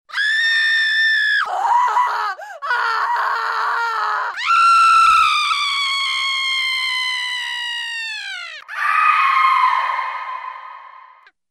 uzhasnyi-krik_24857.mp3